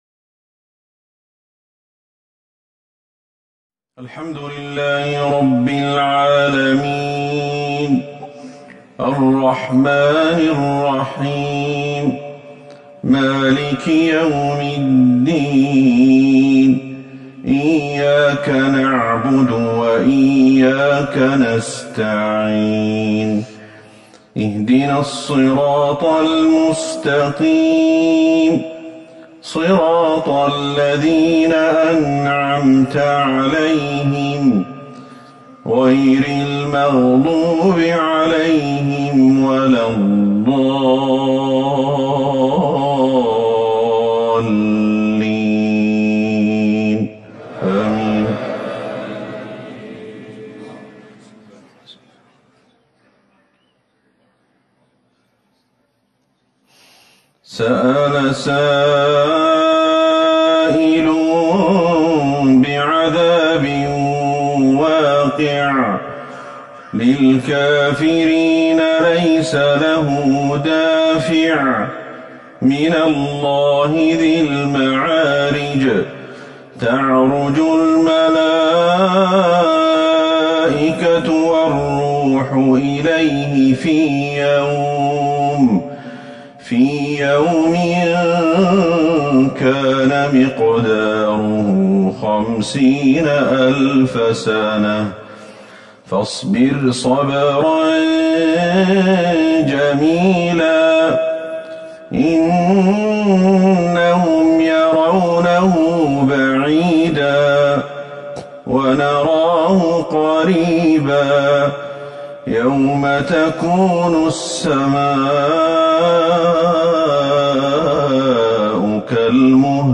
صلاة العشاء ١٩ جمادى الاولى ١٤٤١هـ سورة المعارج Evening prayer 7-1-2020 from Surah Al-Maarij > 1441 هـ > الفروض